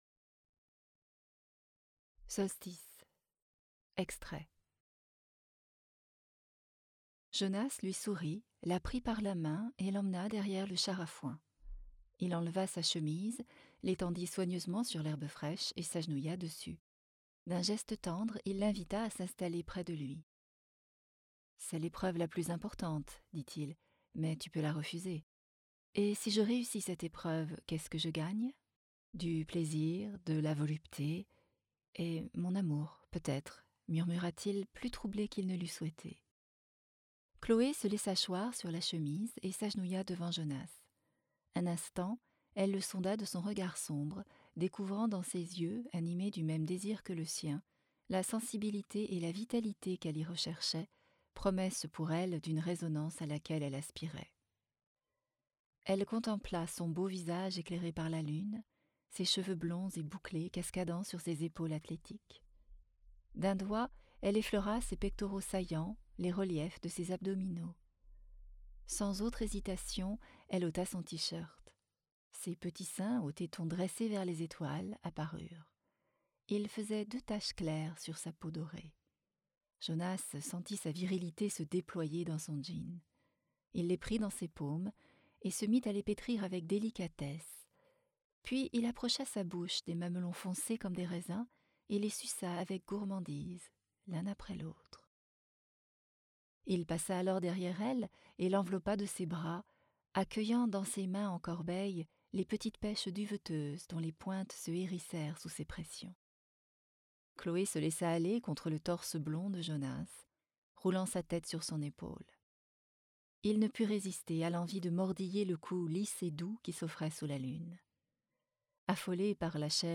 Extraits voix off.